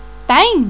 (popular canario)